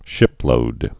(shĭplōd)